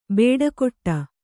♪ bēḍa koṭṭa